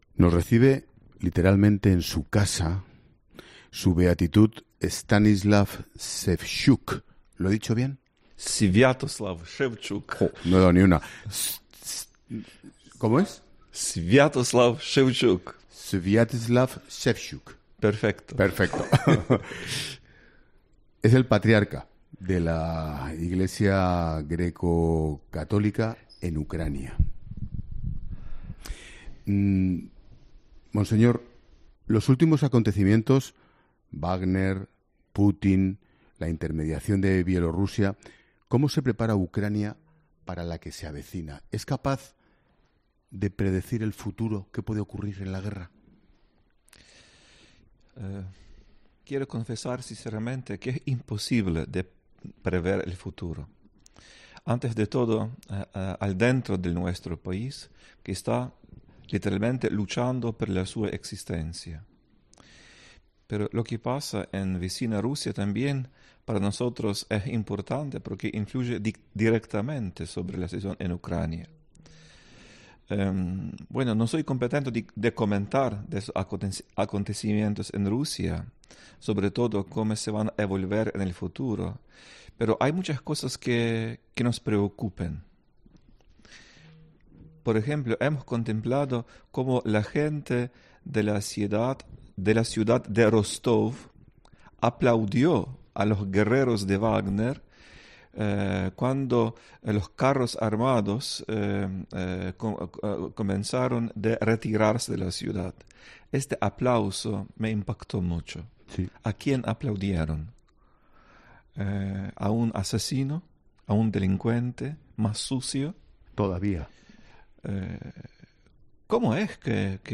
Sviatoslav Shevchuk ha conversado con Ángel Expósito sobre el papel de la iglesia en Ucrania en este año y medio de guerra y la intermediación del Papa...
En su visita a Ucrania y a los diferentes escenarios del horror de la guerra, Ángel Expósito ha podido charlar con Sviatoslav Shevchuk, patriarca de la iglesia greco-católica en Ucrania. El director de 'La Linterna' se ha interesado por conocer su opinión sobre los últimos acontecimientos ocurridos, como la rebelión del grupo Wagner.